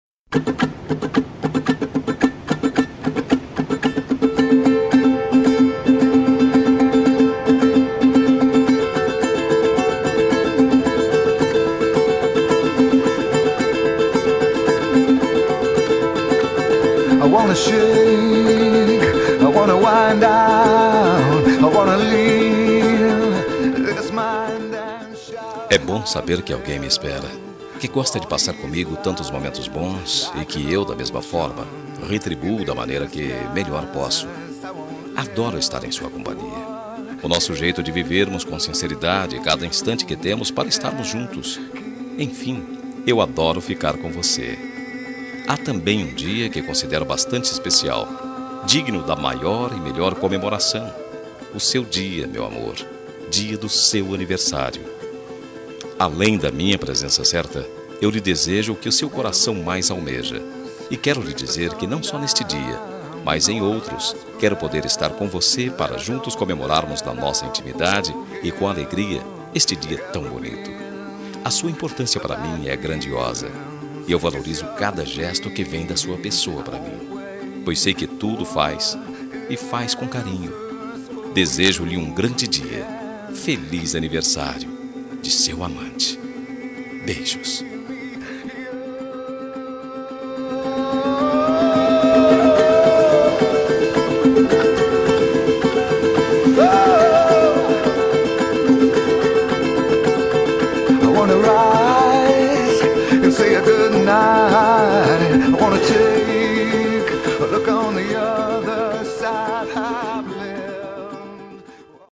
Telemensagem de Aniversário Romântico – Voz Masculina – Cód: 202135 – Amante